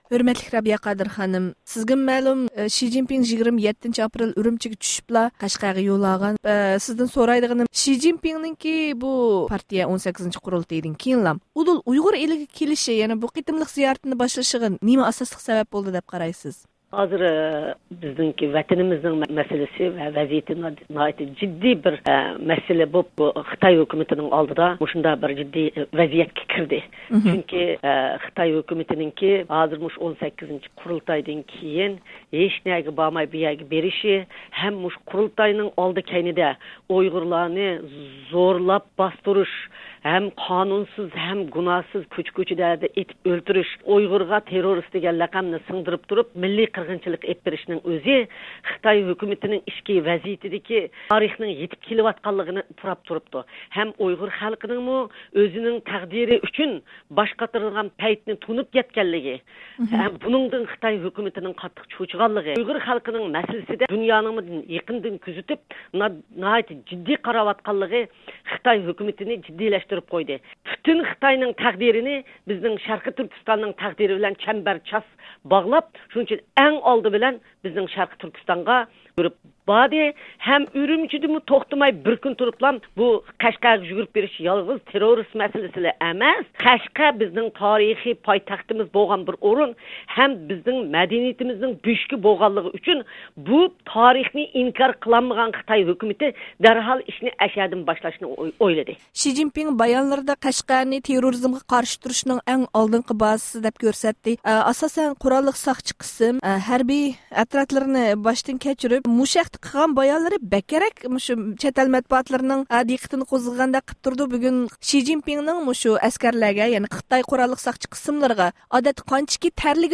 شى جىنپىڭنىڭ ئۇيغۇر ئېلىگە قاراتقان زىيارىتى ۋە بايانلىرىغا قارىتا دۇنيا ئۇيغۇر قۇرۇلتىيى قانداق ئىنكاس قايتۇرىدۇ؟ ئاڭلىغۇچىلىرىمىزغا بۇ ھەقتە مەلۇمات بېرىش يۈزىسىدىن، ئۇيغۇر مىللىي ھەرىكىتى رەھبىرى، دۇنيا ئۇيغۇر قۇرۇلتىيىنىڭ رەئىسى رابىيە قادىر خانىم بىلەن مەخسۇس سۆھبەت ئۆتكۈزدۇق.